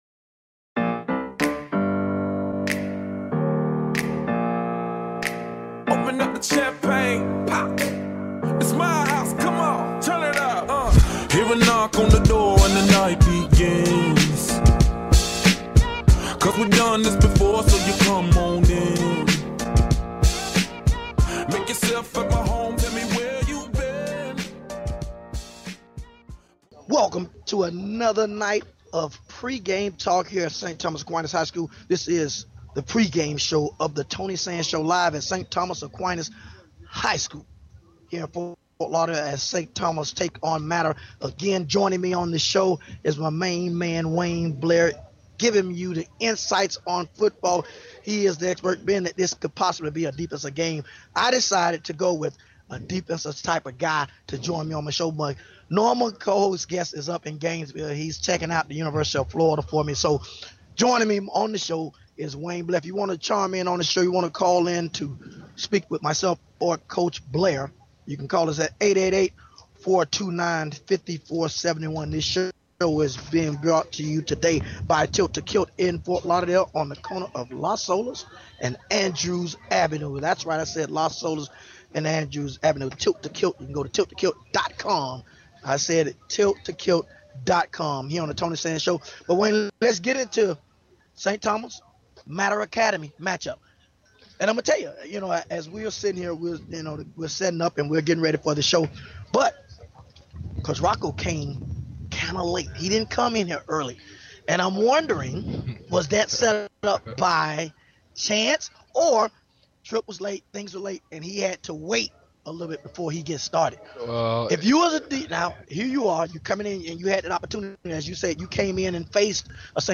Talk Show Episode
at Tilt to Kilt Bar in South Florida - LIVE